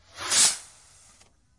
烟花 " 瓶装火箭05
描述：使用Tascam DR05板载麦克风和Tascam DR60的组合使用立体声领夹式麦克风和Sennheiser MD421录制烟花。
Tag: 高手 焰火 裂纹